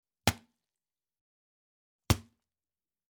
Звук глухого стука по арбузу